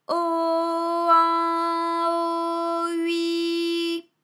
ALYS-DB-001-FRA - First, previously private, UTAU French vocal library of ALYS
o_an_o_ui.wav